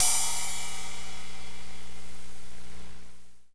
Index of /90_sSampleCDs/Northstar - Drumscapes Roland/CYM_Cymbals 3/CYM_P_C Cyms x